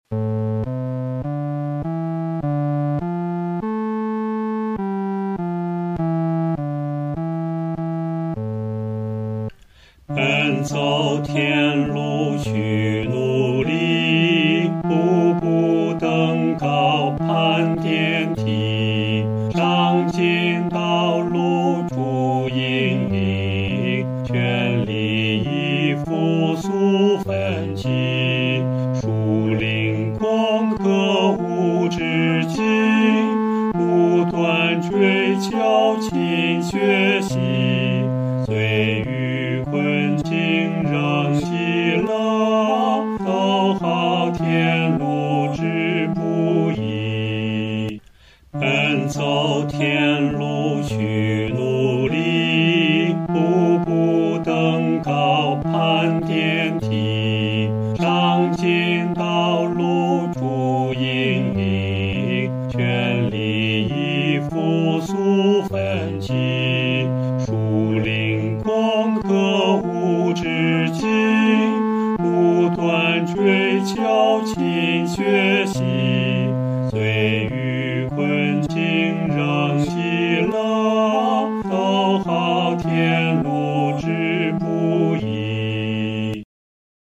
合唱
男低
曲调开始是商调，非常有力，接着转为羽调，作了一个肯定；然后又转为宫调，有一个稳定的半终止。
这首圣诗的弹唱速度不宜太拖沓。